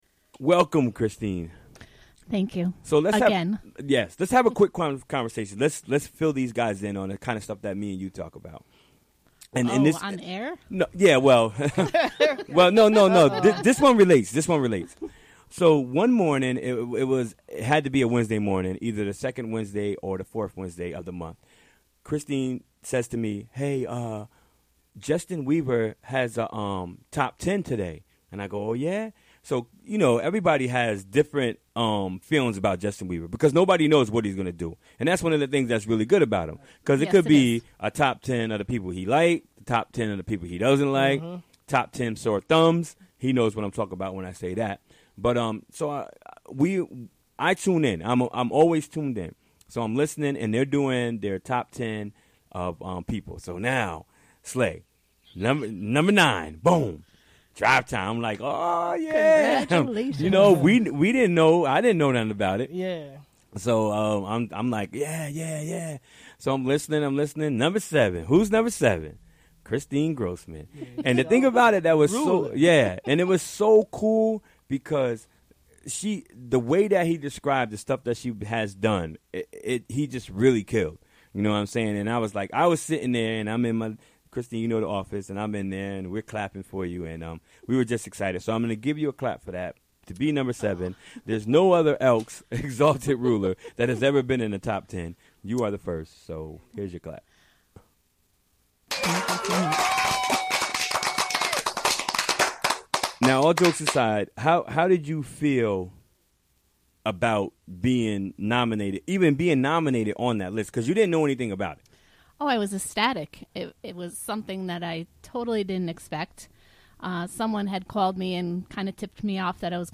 6pm The show features local news, interviews with comm...
Recorded during the WGXC Afternoon Show Wednesday, January 25, 2017.